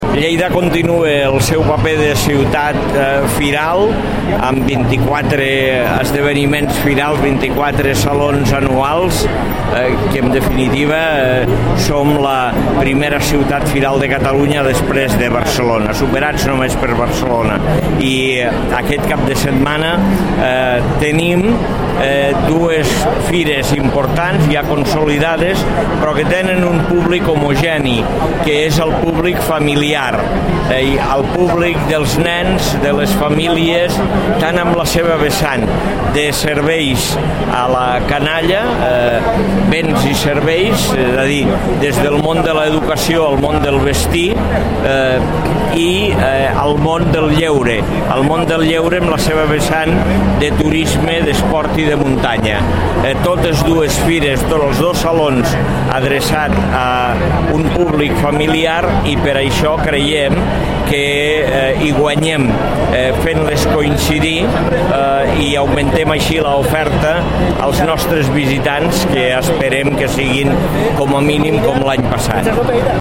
Consulta els talls de veu (en format MP3) de l'Alcalde i dels diferents regidors de l'Ajuntament de Lleida
Tall de veu A. Ros